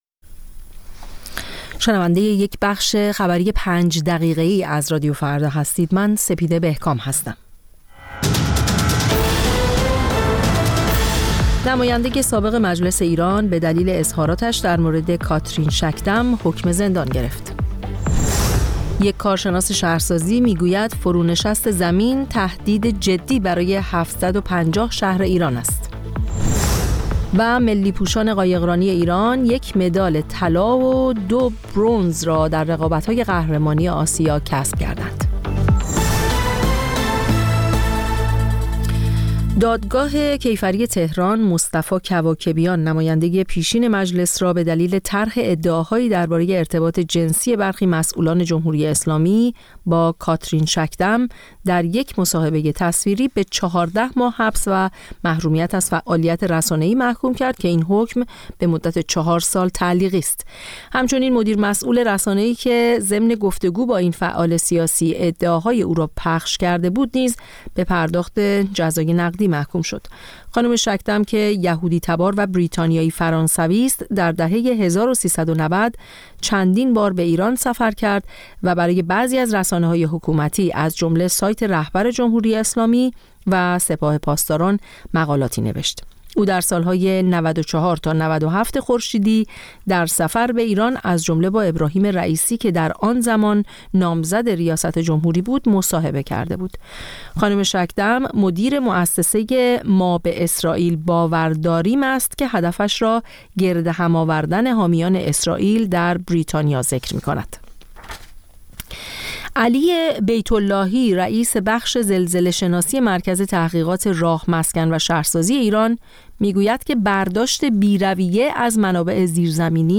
سرخط خبرها ۰۰:۰۰